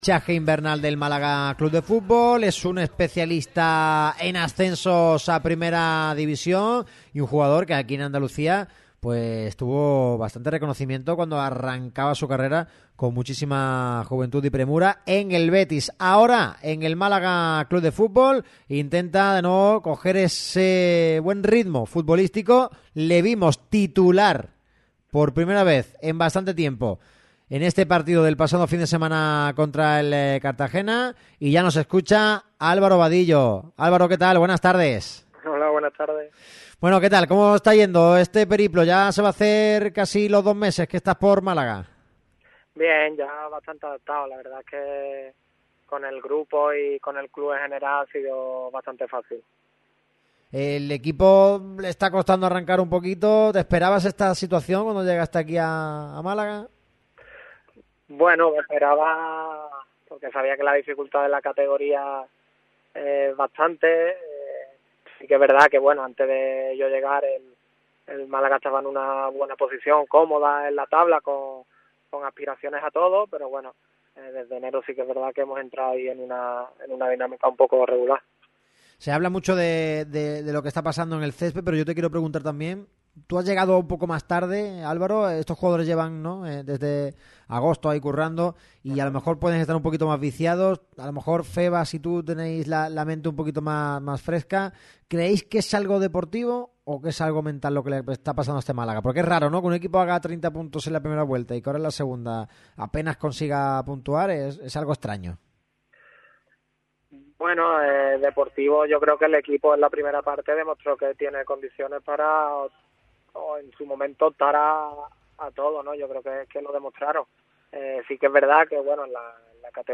El extremo gaditano pasó por los micrófonos de Radio MARCA Málaga y analizó cómo se encuentra el vestuario tras el último empate ante el Cartagena. El futbolista también habló de las próximas citas de los malaguistas, de Natxo González y de su situación personal.